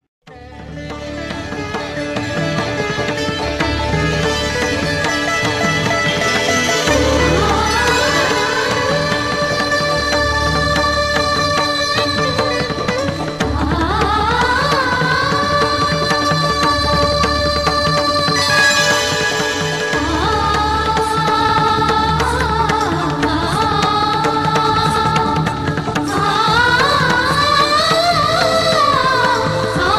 best flute ringtone download
romantic ringtone download
melody ringtone